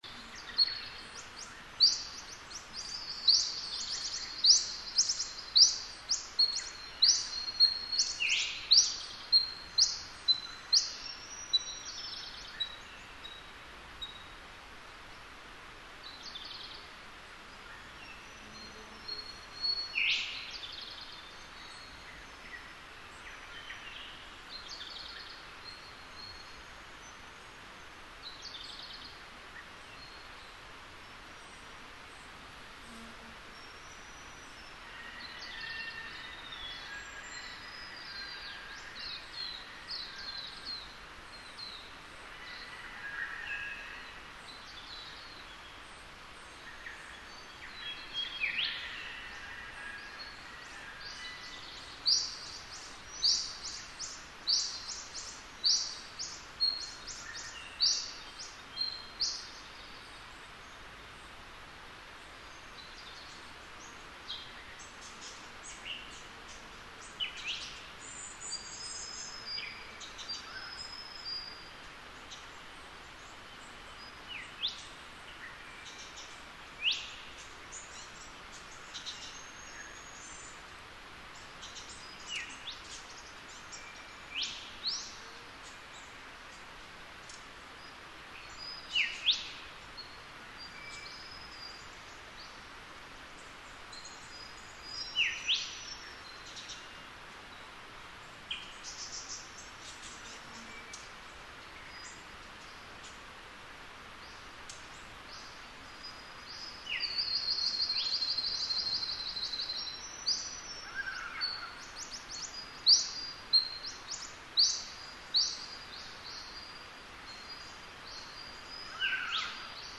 Soundcape of an Ancient Wilderness
"We hope this 75 minute nature soundscape, recorded in the diverse habitats of Tasmania's Tarkine, will give you a feeling for this unique wild place, which is sadly under imminent threat.
Birdsong of the Great Southern Rainforest
Headphones are great, as you'll be fully immersed with a sense of the space around you.